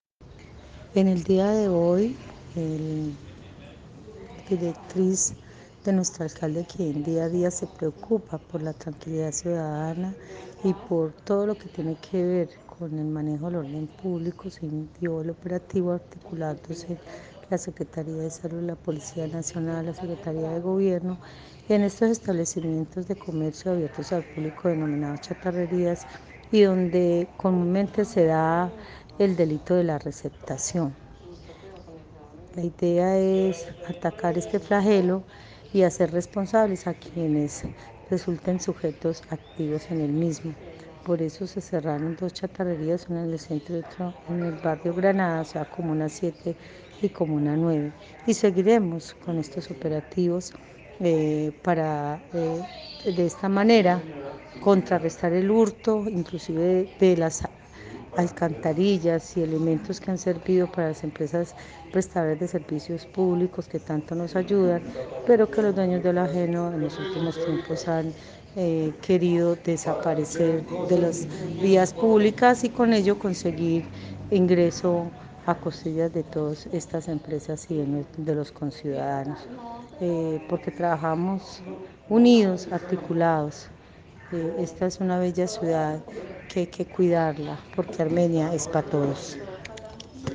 Audio: Gloria Cecilia García, Secretaria de Gobierno